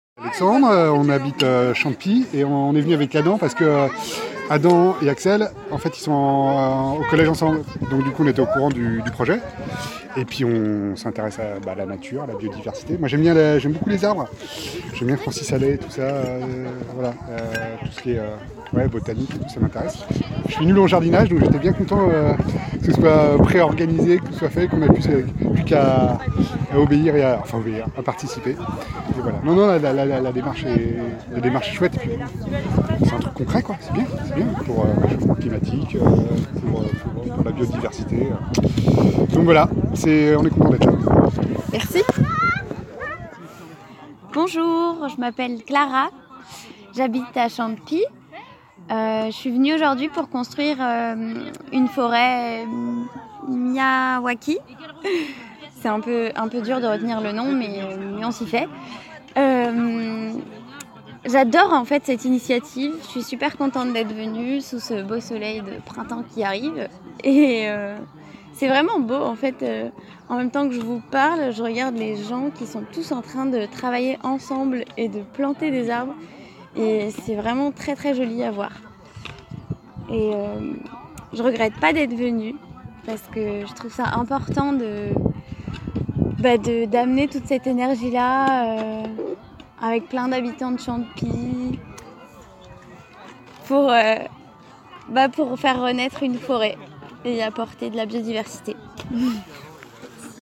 Au stand café-biscuits, je m’approche de quelques habitants :
par habitant·e·s de Chantepie